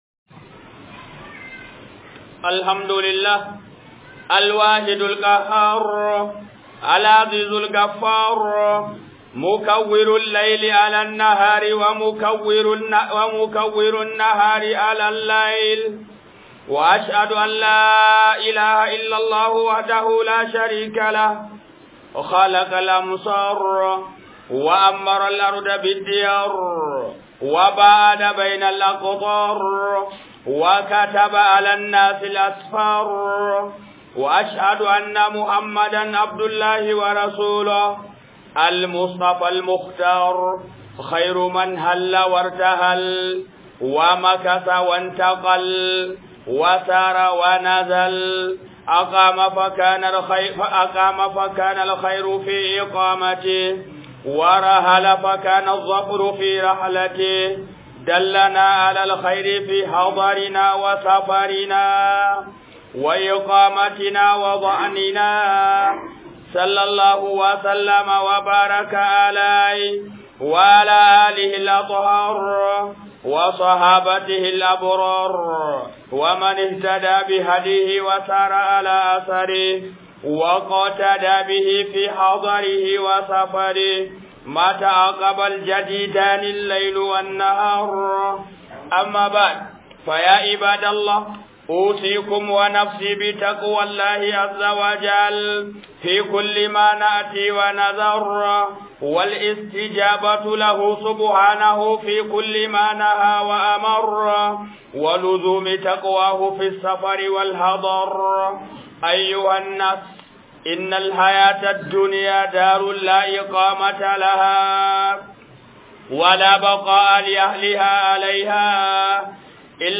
393 Khudban Jumma'a Akan Tafiye-tafiye